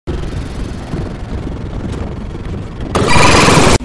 Scary Huggy Wuggy Sound Effect Free Download